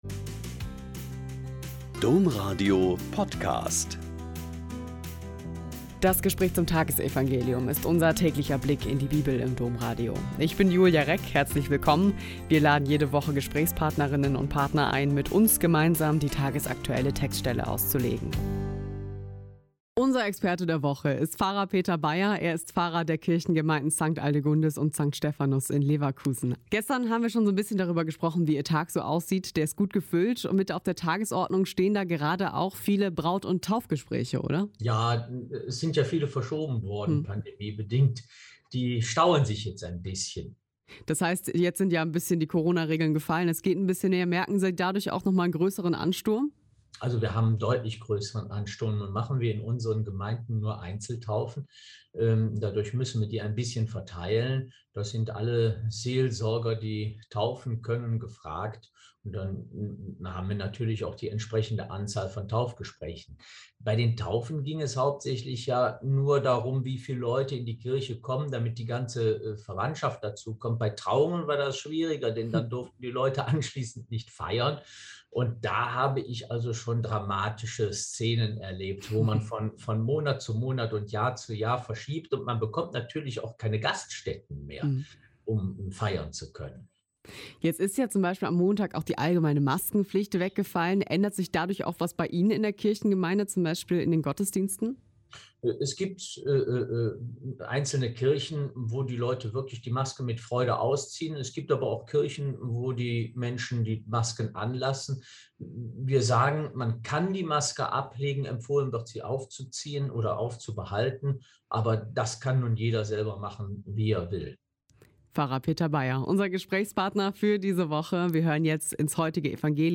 Joh 8,31-42 - Gespräch